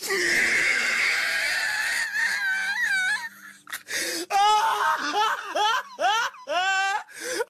laugh2.ogg